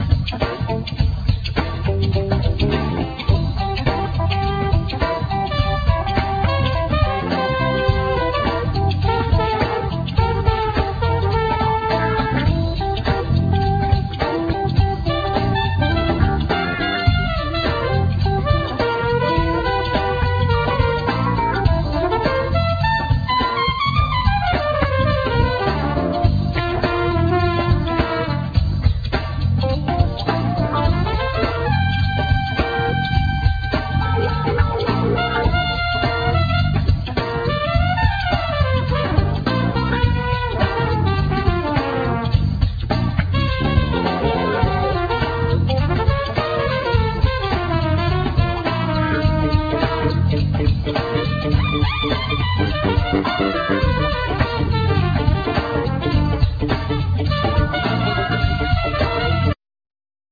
Trumpet
Turntables
Drums
Bass
Fender piano
Synthsizer, Hammond organ
African percussions
Guitar